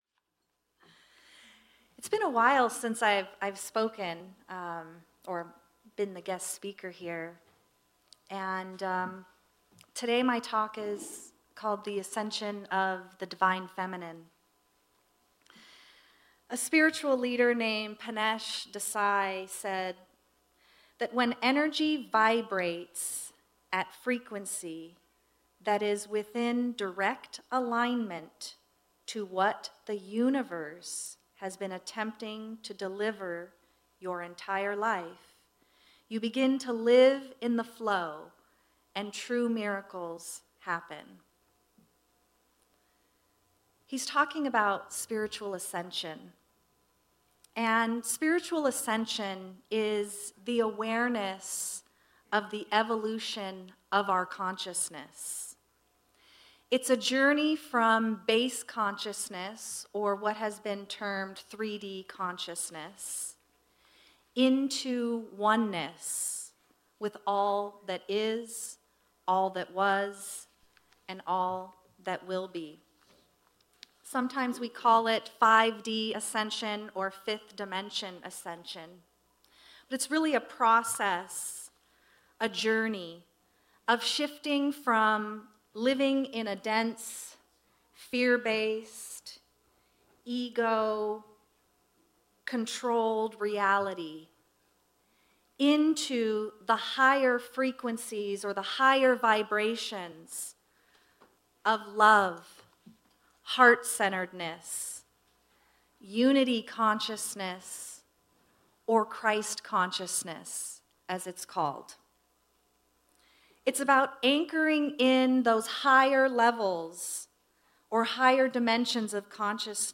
The audio recording (below the video clip) is an abbreviation of the service. It includes the Message, Meditation, and Featured Song.